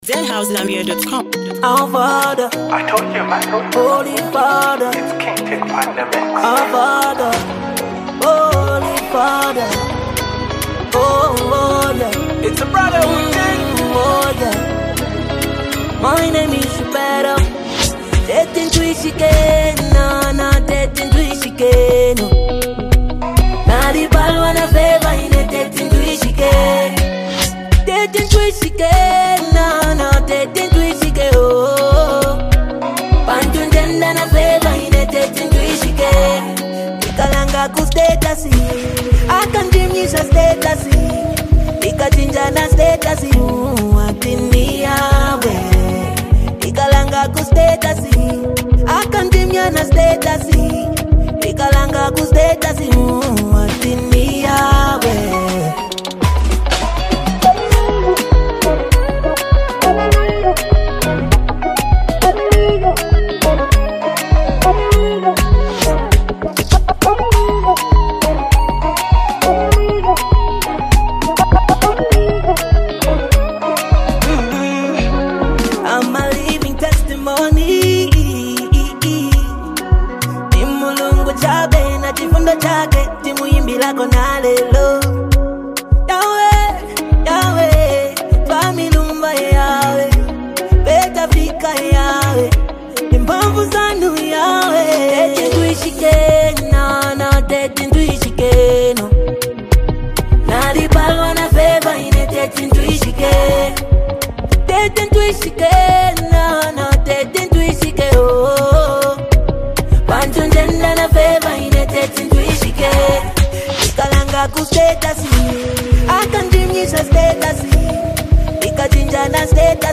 a smooth blend of melody, emotion, and class.